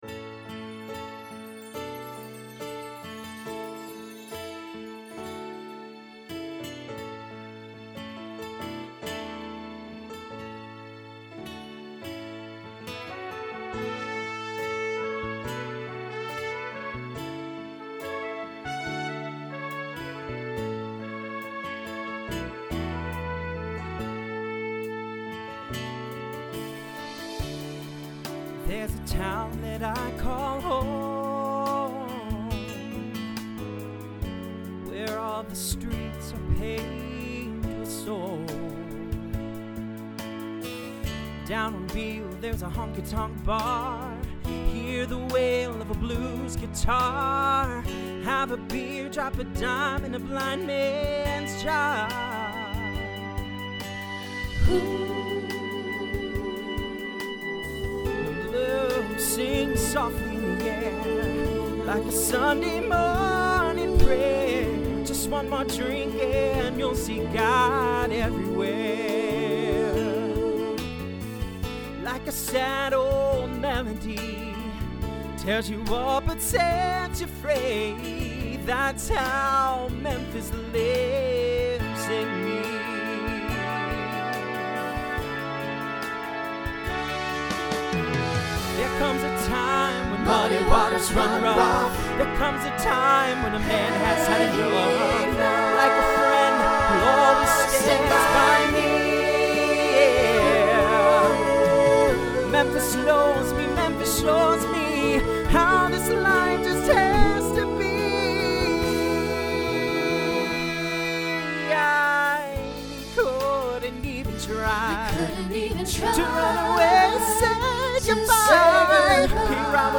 New SSA voicing for 2020